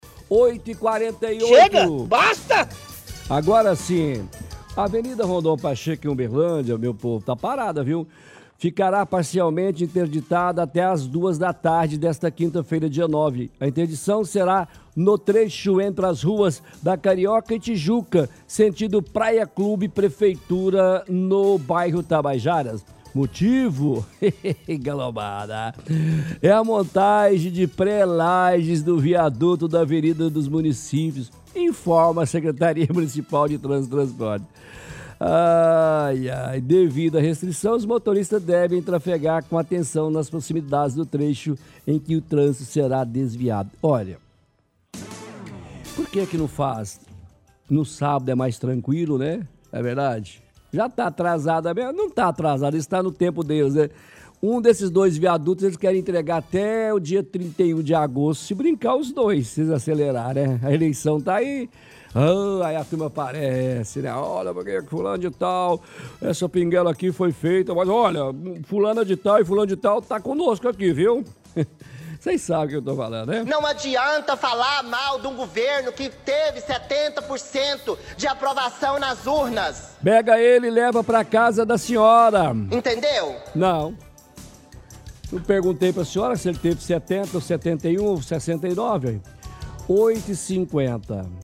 Ri enquanto faz a leitura.